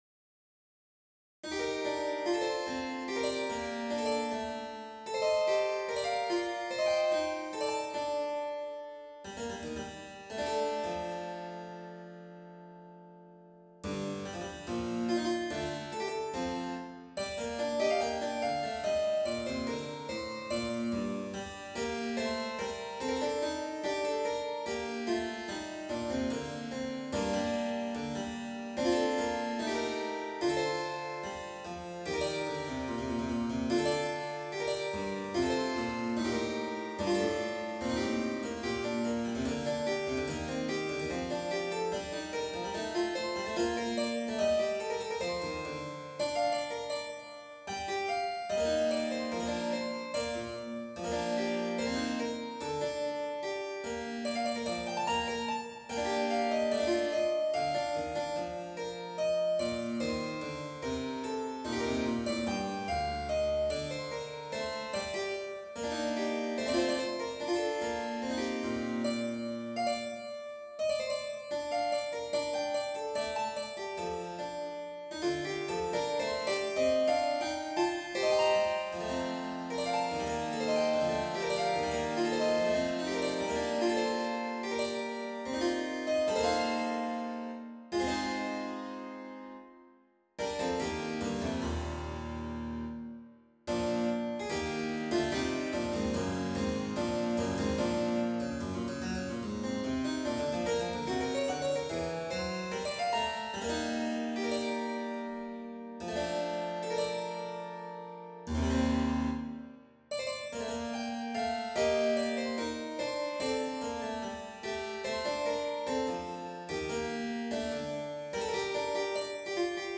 For the fun of it, it became theme for this little set of variations conceived for harpsichord.
5 pages, circa 6' 00" - an MP3 demo is here: